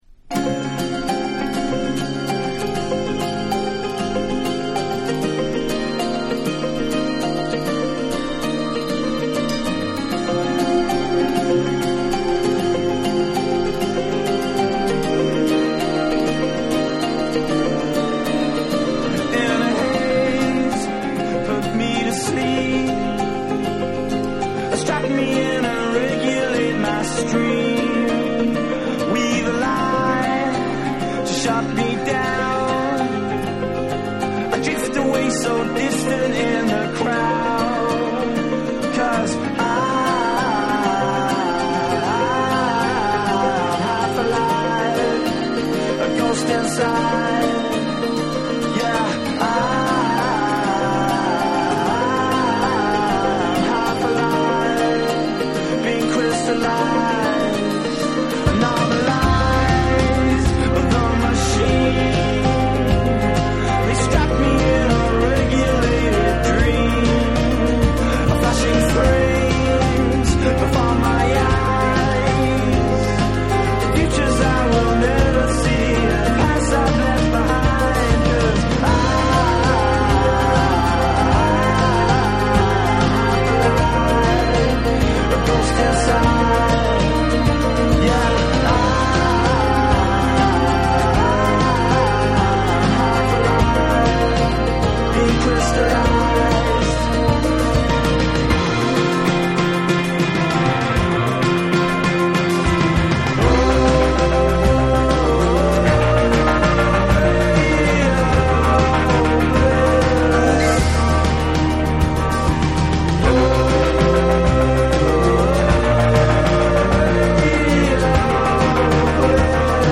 哀愁のギター & シンセ、ヴォーカルが三位一体となり展開するエモーショナルなメロディアス・ロック・チューン
NEW WAVE & ROCK